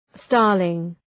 Shkrimi fonetik {‘stɑ:rlıŋ}